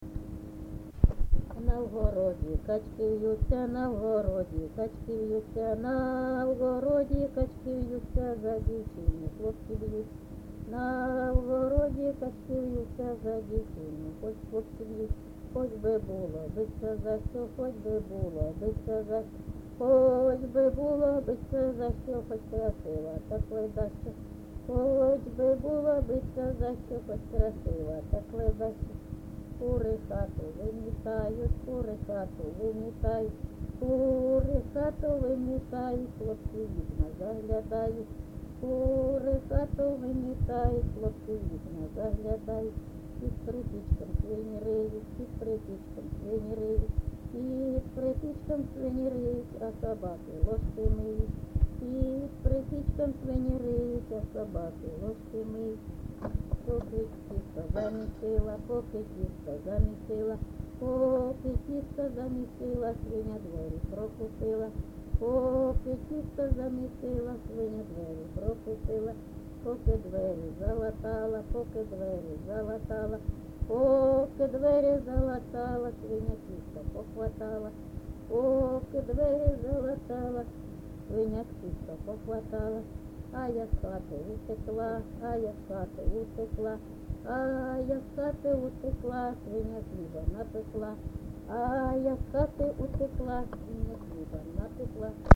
ЖанрТриндички, Жартівливі
Місце записус. Хрестівка, Горлівський район, Донецька обл., Україна, Слобожанщина